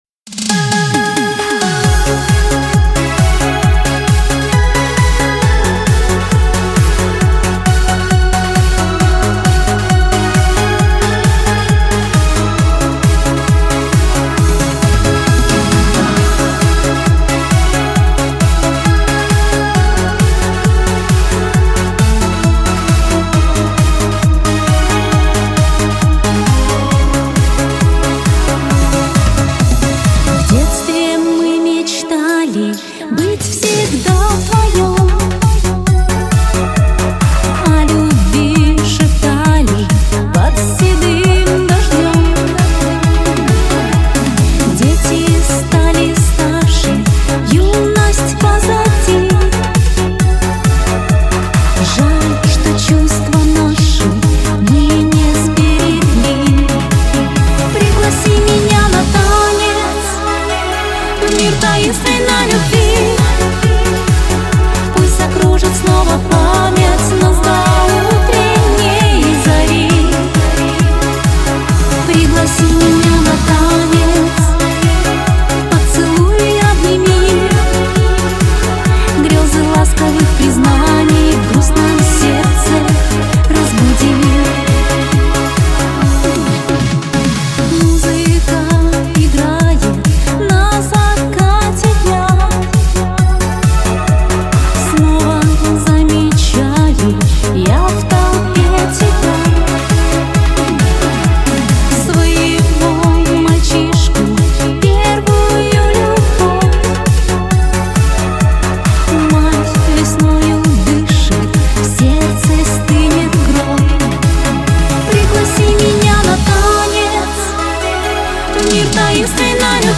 Скачать музыку / Музон / Песни Шансона